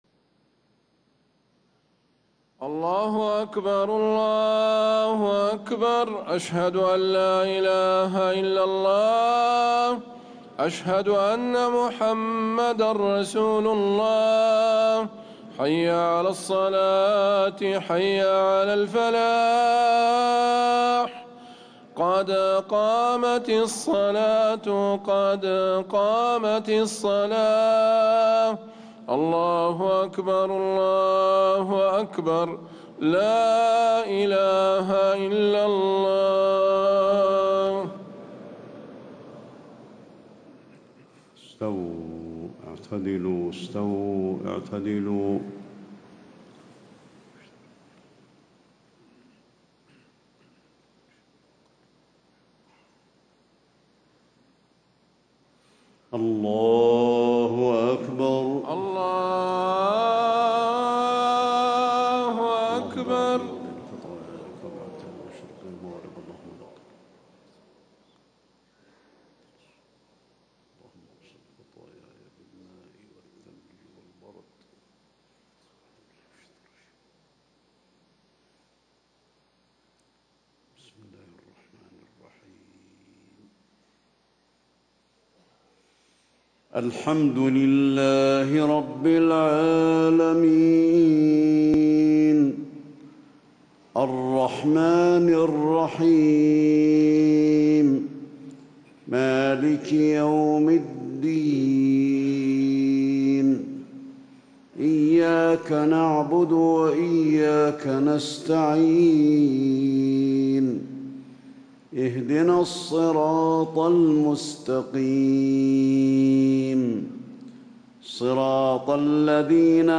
صلاة الفجر 1 ذو الحجة 1437هـ فواتح سورة الواقعة 1-74 > 1437 🕌 > الفروض - تلاوات الحرمين